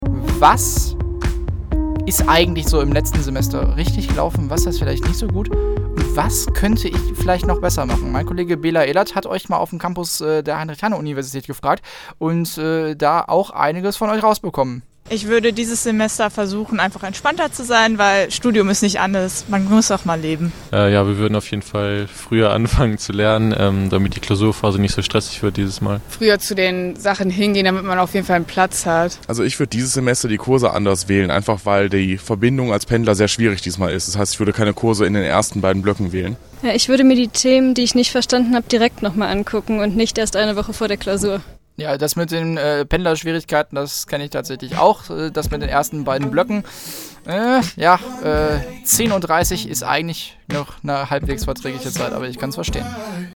Umfrage